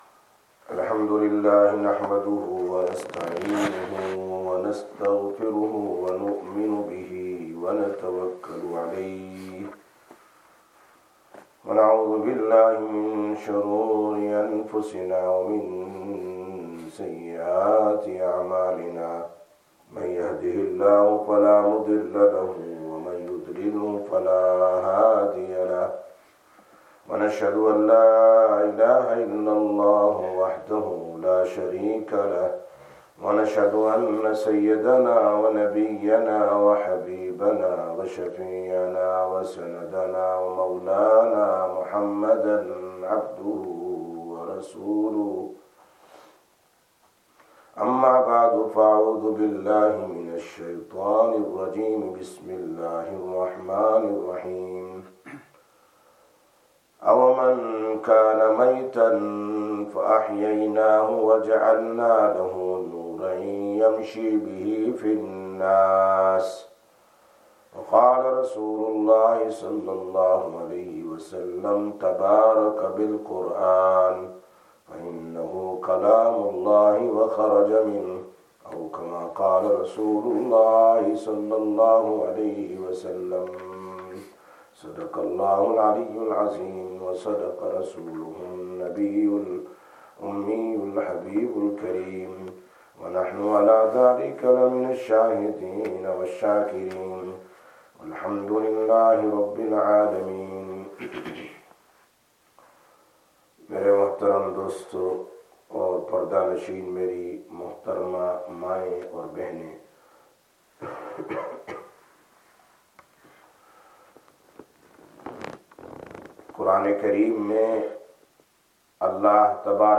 20/03/2024 Sisters Bayan, Masjid Quba